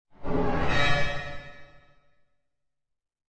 Descarga de Sonidos mp3 Gratis: espacial 1.
descargar sonido mp3 espacial 1